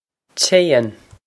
Téann Chayn
This is an approximate phonetic pronunciation of the phrase.